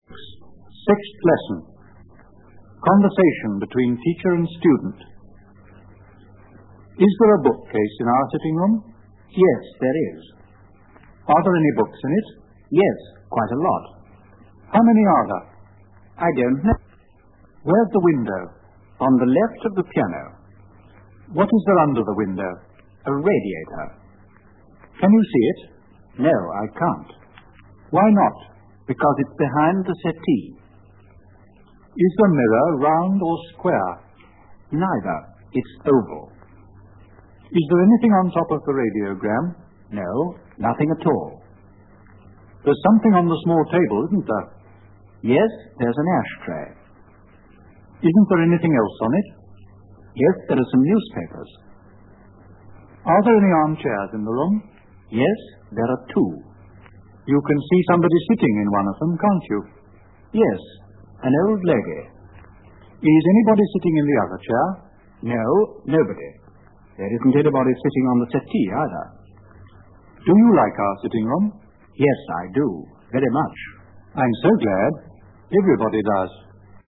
06 Our sitting-room (Conversation)